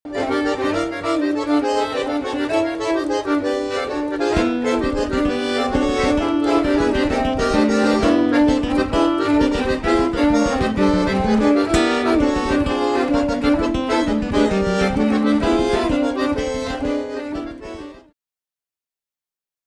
acc. git
accordion
cello